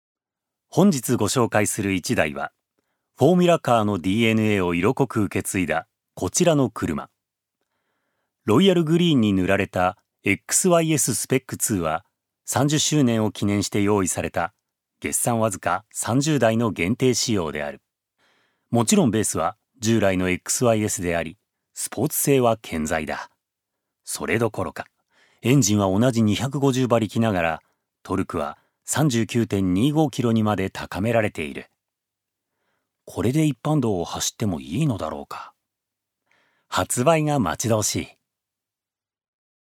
所属：男性タレント
ナレーション４